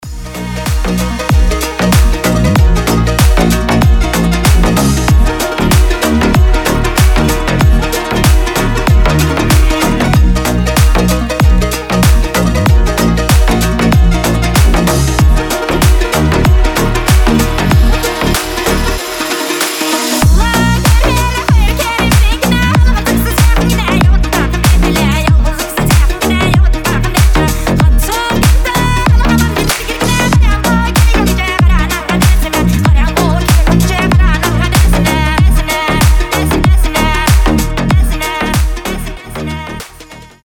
• Качество: 320, Stereo
Bass House
этнические
ремиксы
Калмыцкая народная песня в крутой обработке